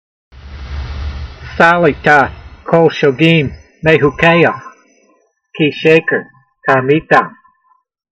Sound (Psalm 119:118) Transliteration: sa lee ta kol -sho geem may hu k ey ha , kee - shey ker tarmee t am Vocabulary Guide: You have counted as nothing all who err from your statute s: For their deceitfulness is falsehood . Translation: You have counted as nothing all who err from your statutes: For their deceitfulness is falsehood.